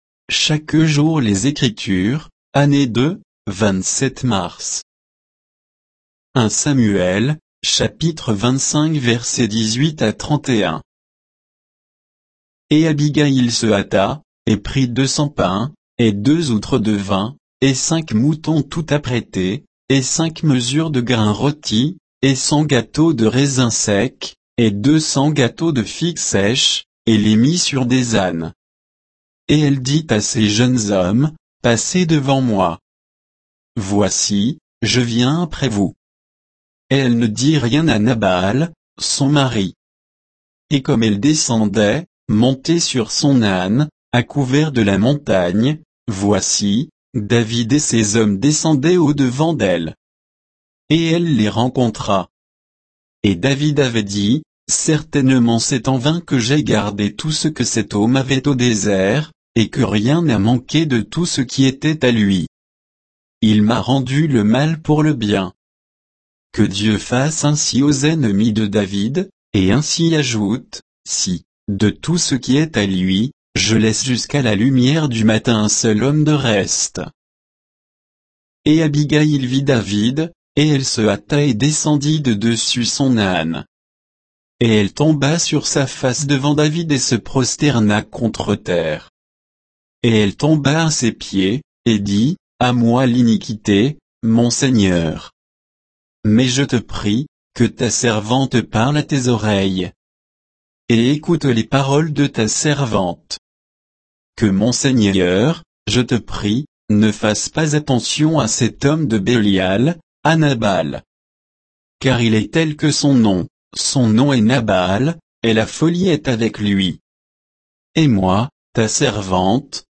Méditation quoditienne de Chaque jour les Écritures sur 1 Samuel 25